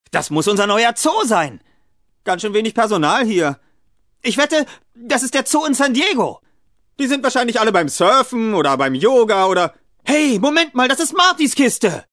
Also, the German dubbing voices of Ben Stiller and Jada Pinkett-Smith could be hired for the computer game.